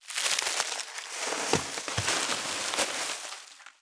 663コンビニ袋,ゴミ袋,スーパーの袋,袋,買い出しの音,ゴミ出しの音,袋を運ぶ音,
効果音